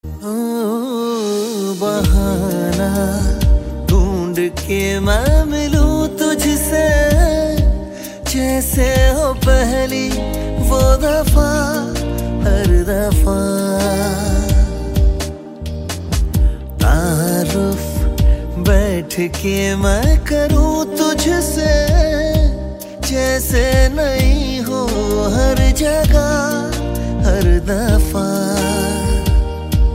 Categories Hindi ringtones